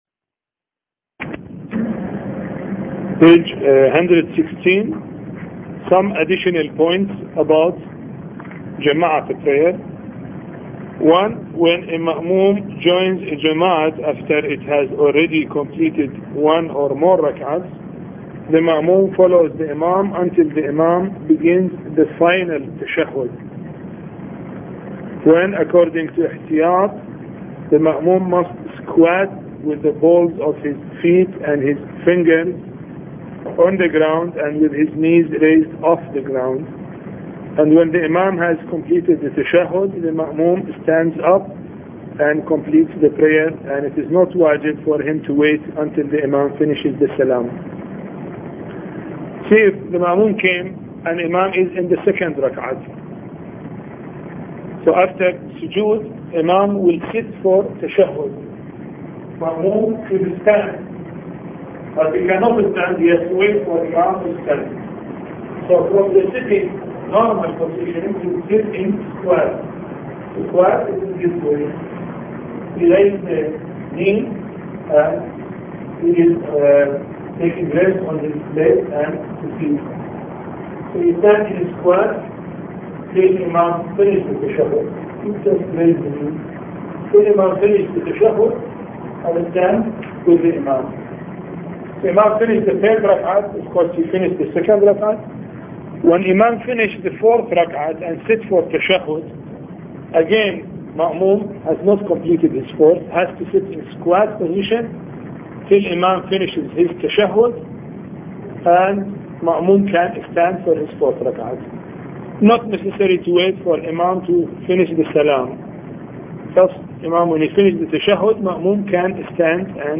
A Course on Fiqh Lecture 19